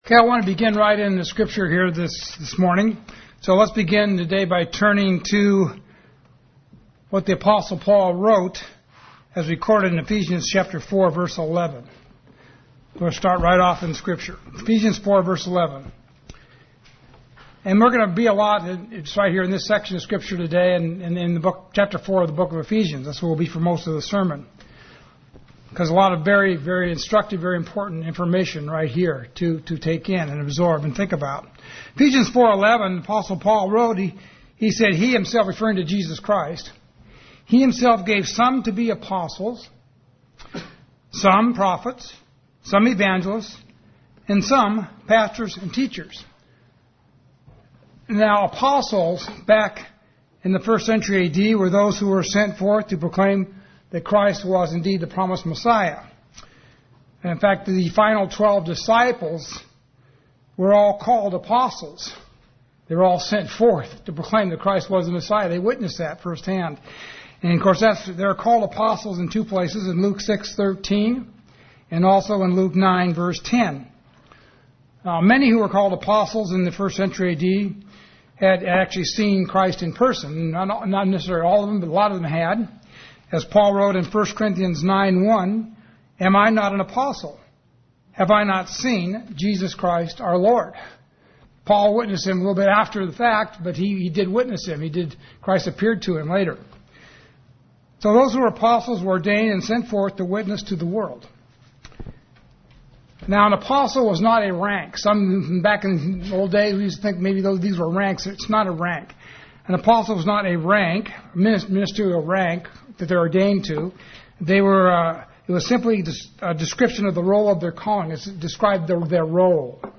| United Church of God